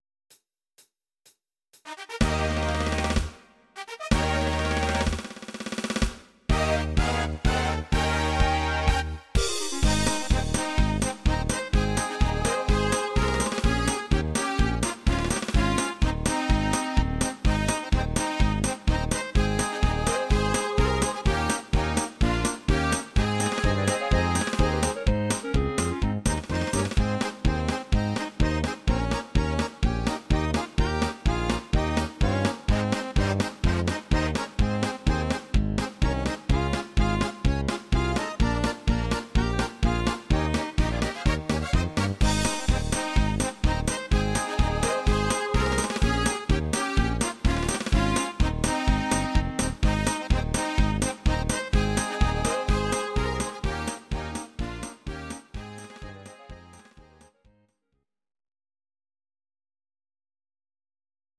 Audio Recordings based on Midi-files
German, Traditional/Folk, Volkstï¿½mlich